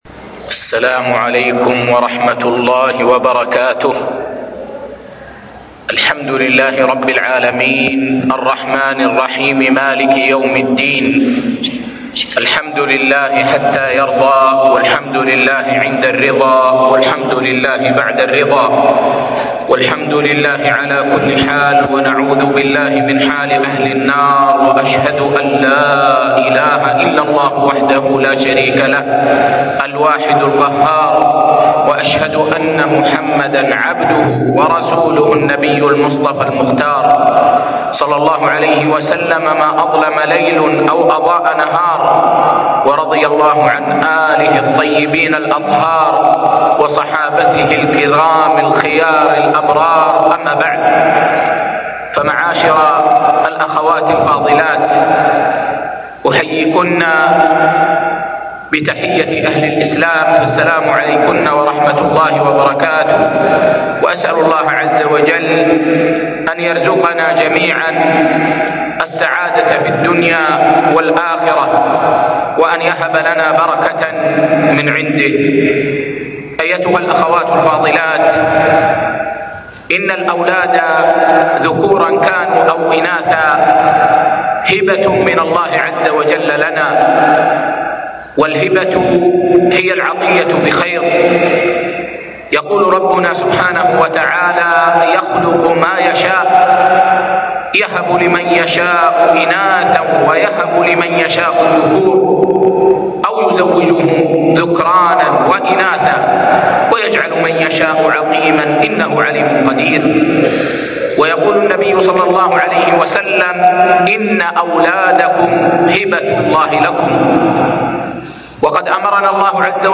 تربية الأبناء - محاضرة